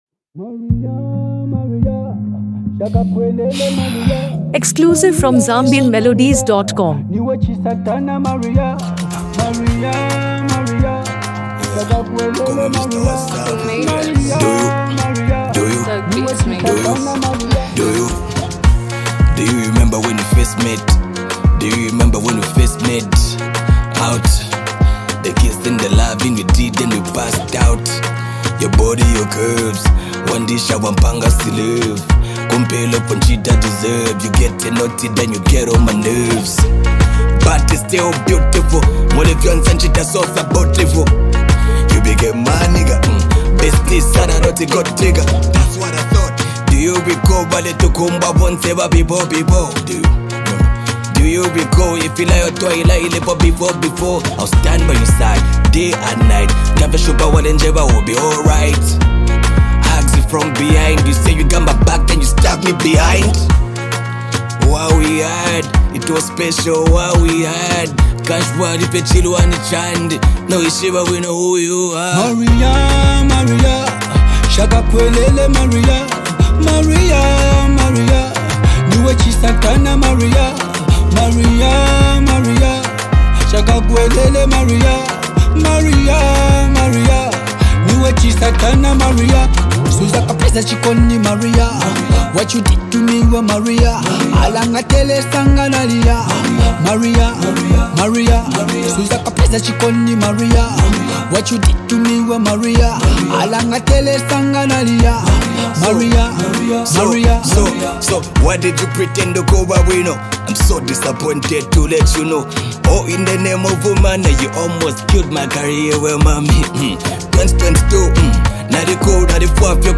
emotionally gripping track